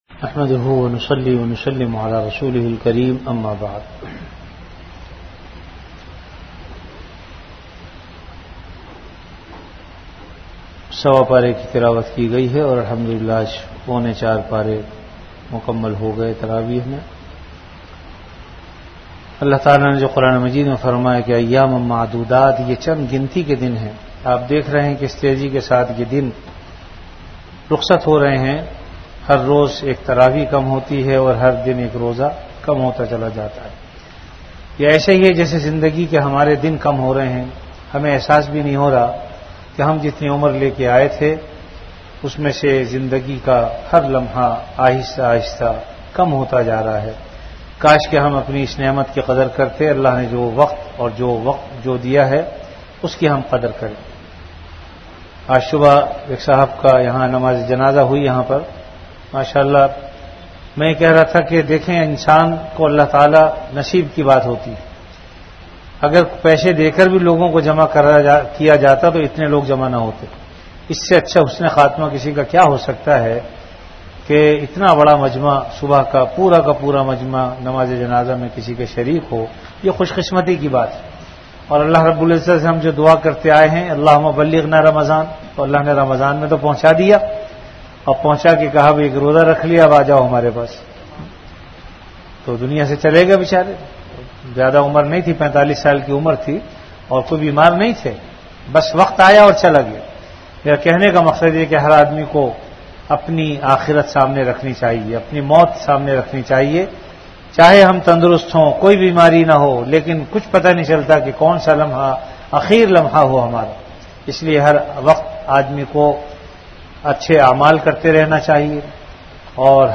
Delivered at Jamia Masjid Bait-ul-Mukkaram, Karachi.